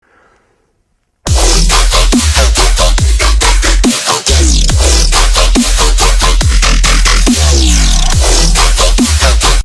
how_dubstep_was_discovered_extra_terra_music_ekmcograbfrom.mp3